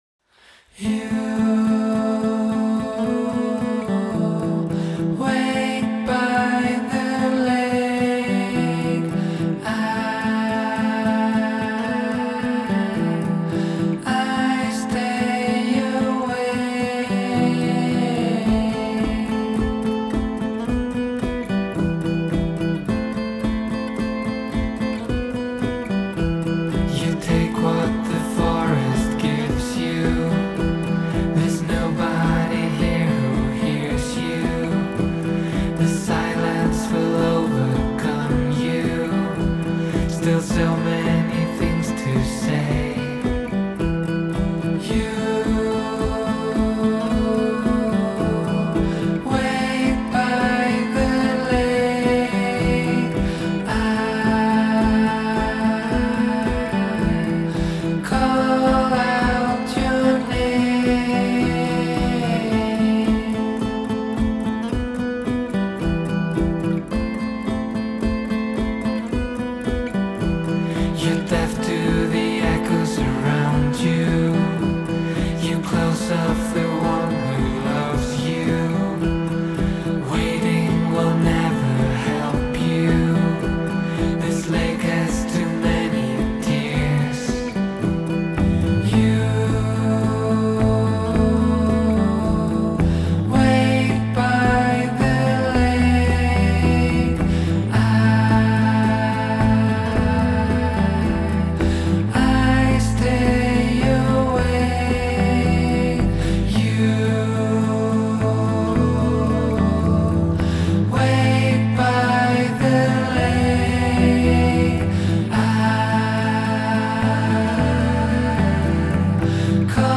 Genre: Electronic, Indie Pop